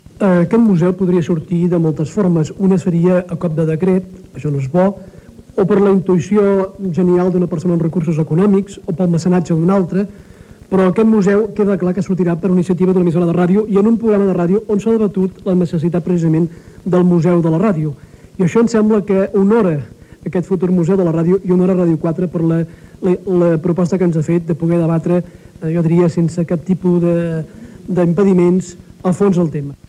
El director general de radiodifusió i televisió de la Generalitat de Catalunya, Agustí Gallart, sobre la petició de creació del Museu de la Ràdio a Catalunya.
El dissabte 22 d'abril "L'altra ràdio" va enregistrar a la Rectoria Vella de Sant Celoni un espai cara al públic per celebrar els 15 anys del programa i demanar la creació del Museu de la ràdio a Catalunya.